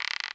bow_ready.wav